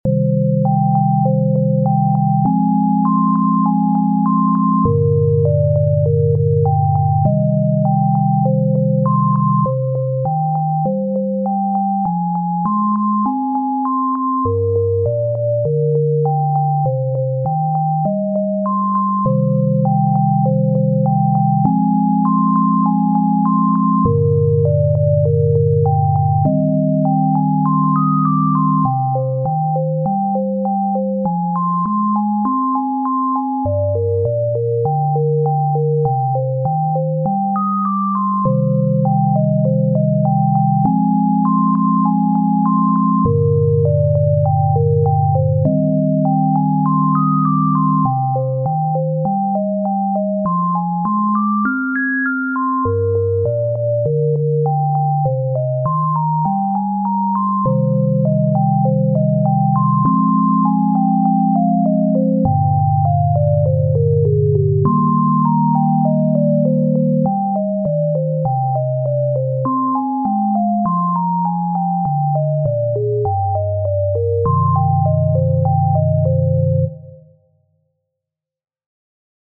This one uses a sine wave generator and utilizes cords more. It's written in C minor and uses 100 bpm. The feeling I wanted to convey here is curiosity, but also fright.